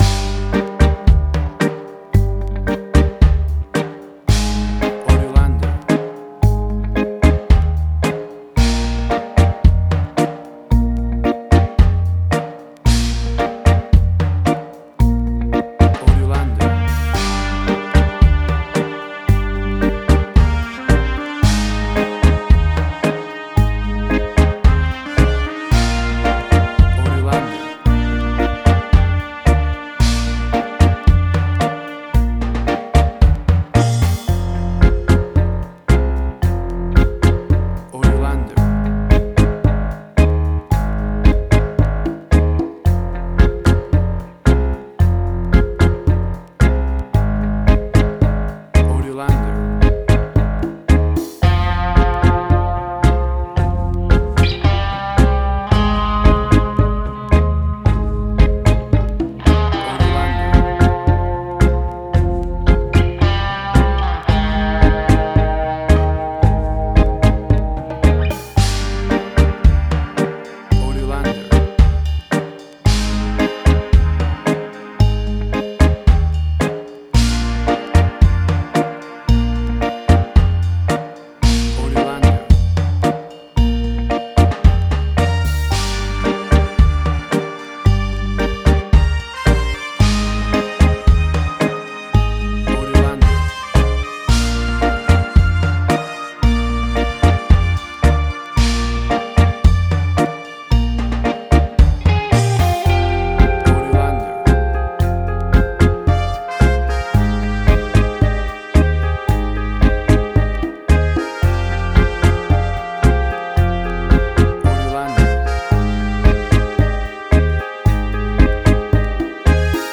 Reggae caribbean Dub Roots
Tempo (BPM): 56